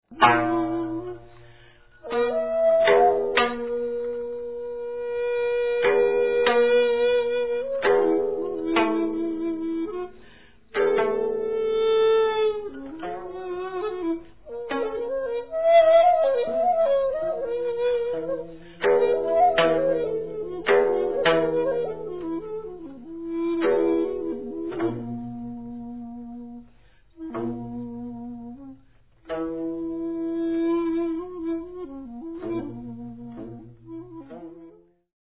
Fascinating and diverse Korean traditional orchestral music.
Recorded in Seoul, Korea.